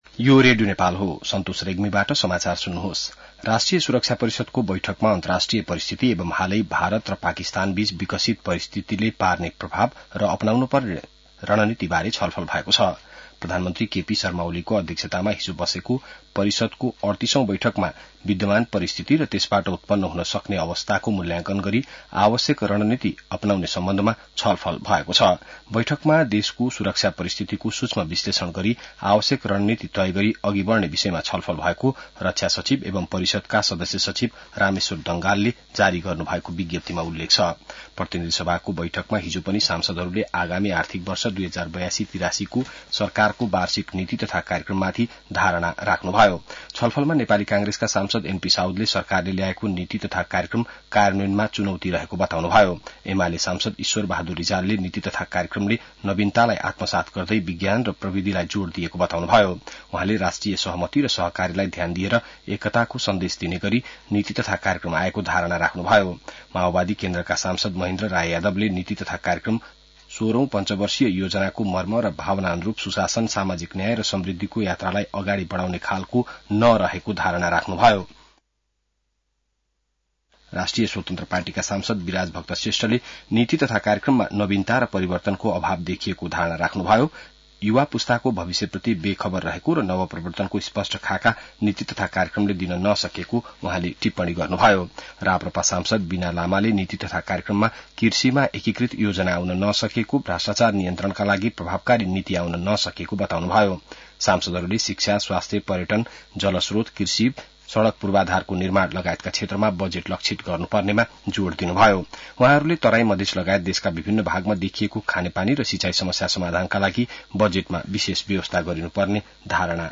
बिहान ६ बजेको नेपाली समाचार : २५ वैशाख , २०८२